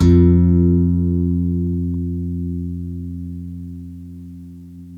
GUITARRON02R.wav